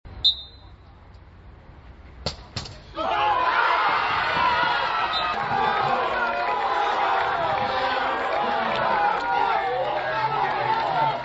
la felicidad hasta entonces contenida se desata formato MP3 audio(0,09 MB).